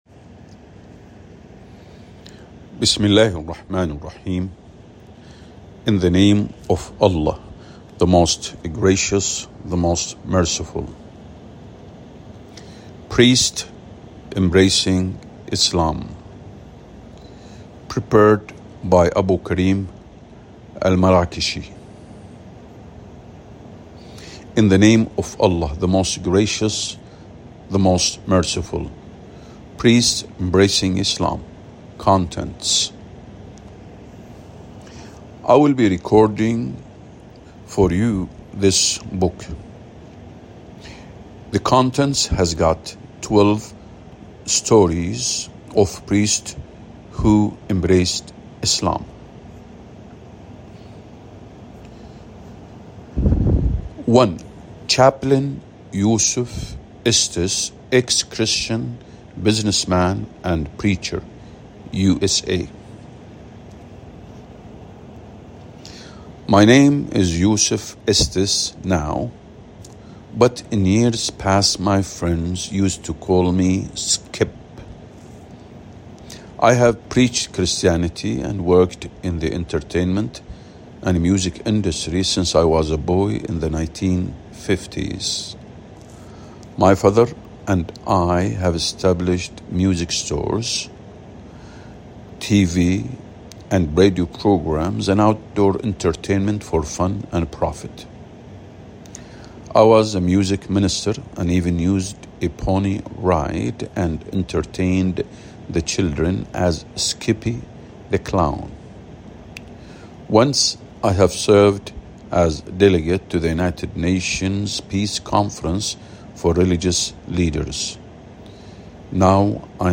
priests-embracing-islam_audio-book_english_1.mp3